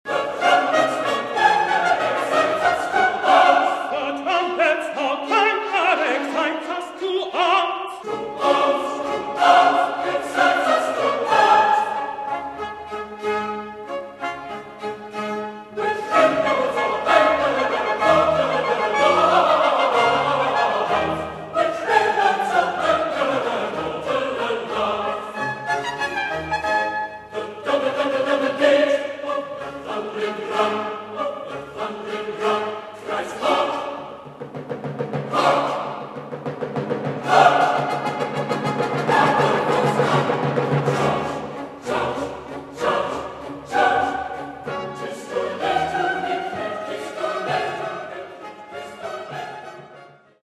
Tallinna Barokkorkester
Ansambel Studio Vocale
sopran
tenor